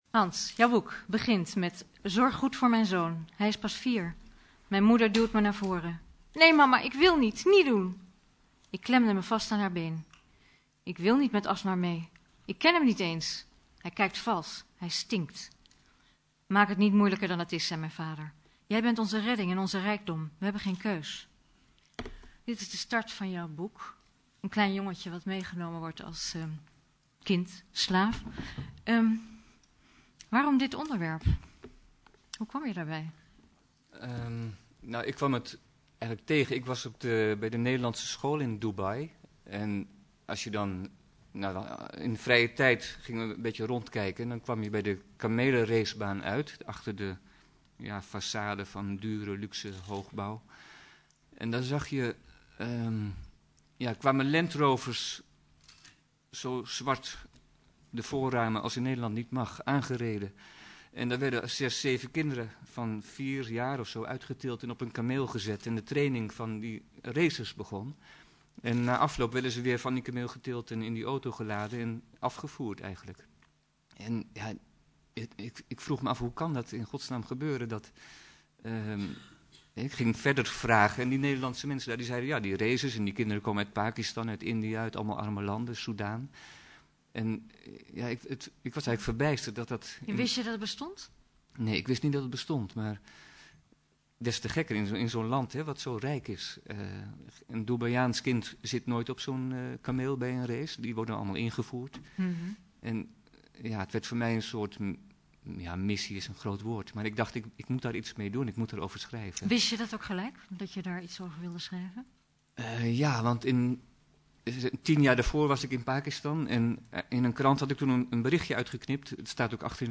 Uitreiking Woutertje Pieterse Prijs 2007